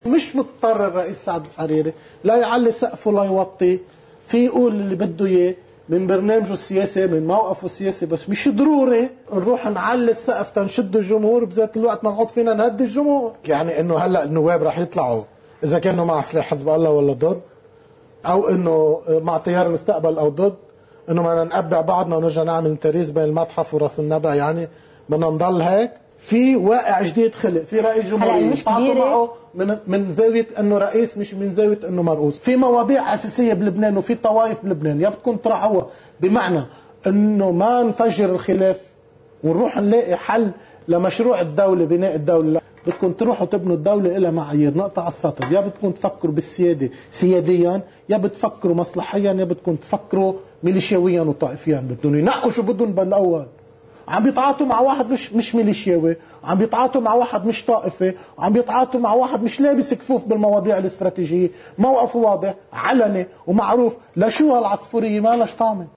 مقتطف من حديث النائب زياد اسود لقناة “الجديد”: